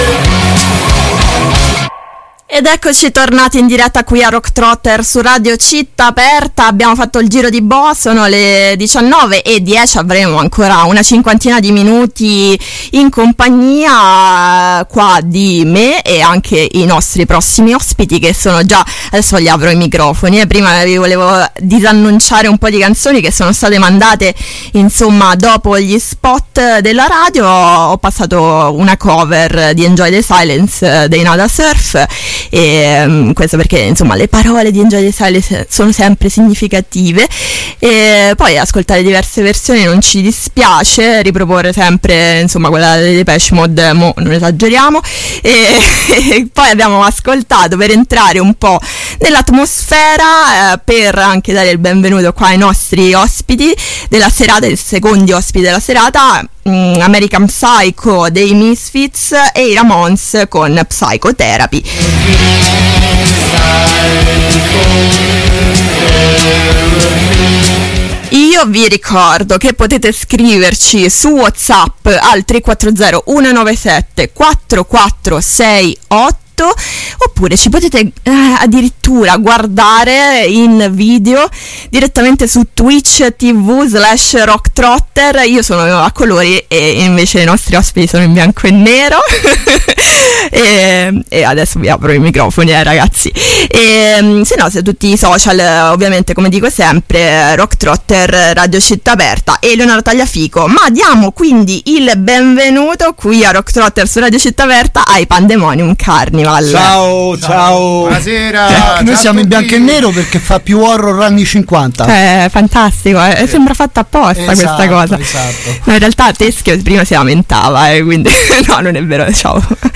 L’intervista è stata anche un’occasione per parlare dell’uscita del loro secondo lavoro, di cui abbiamo ascoltato qualche traccia.